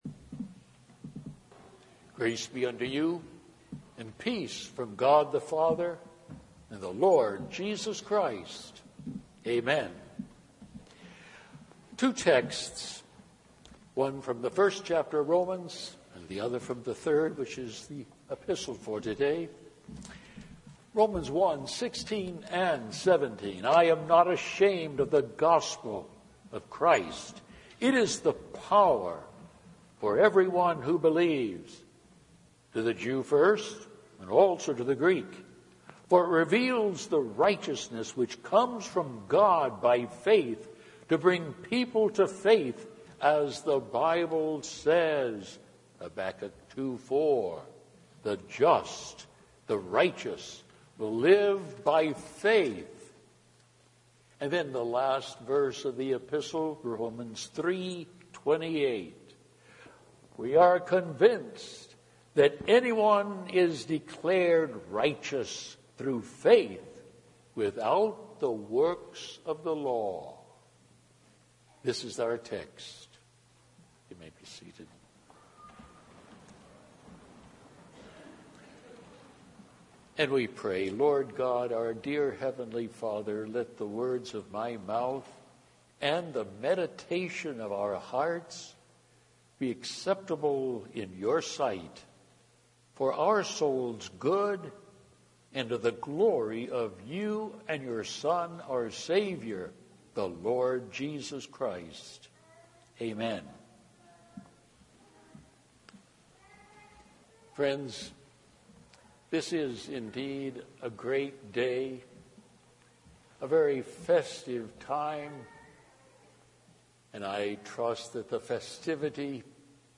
Romans 1:16-17, Romans 3:28 Audio Sermon http